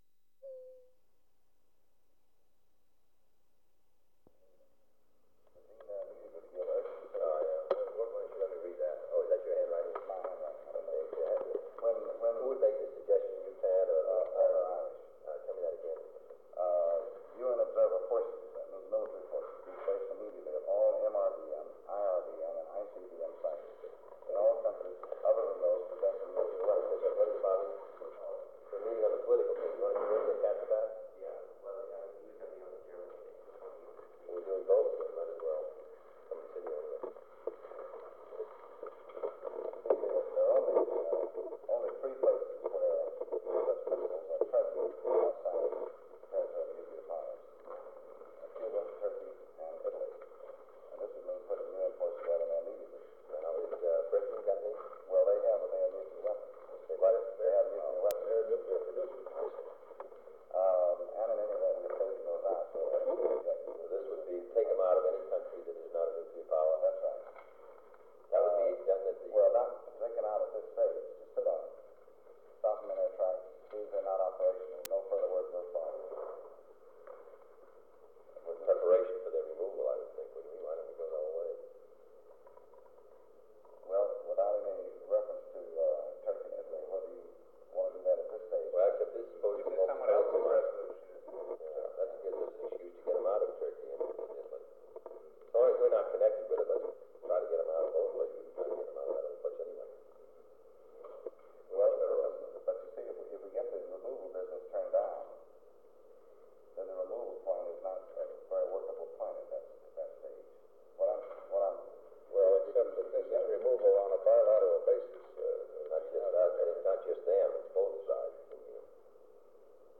Secret White House Tapes | John F. Kennedy Presidency Meeting on Diplomatic Plans for the Crisis Rewind 10 seconds Play/Pause Fast-forward 10 seconds 0:00 Download audio Previous Meetings: Tape 121/A57.